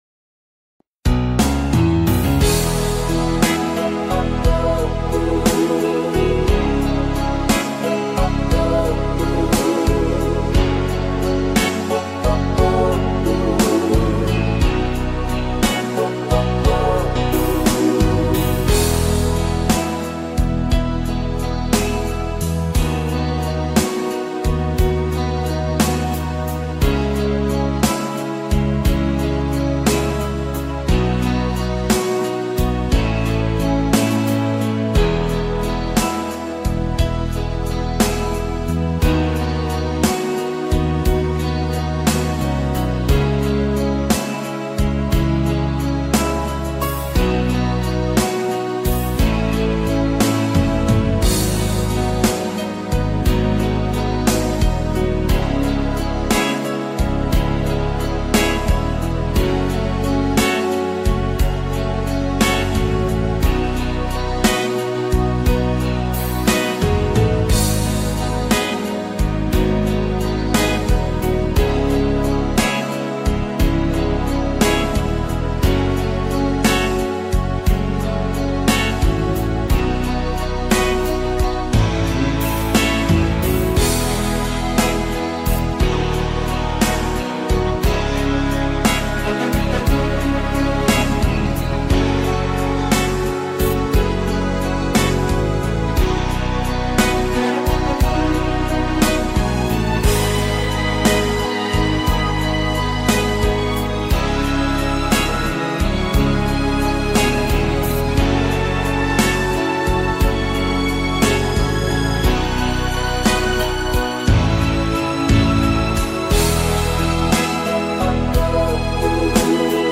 Karaoke Version
Instrumental Cover